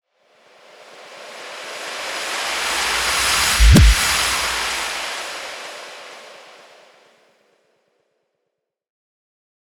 BWB UPGRADE3 FX RISE (10).wav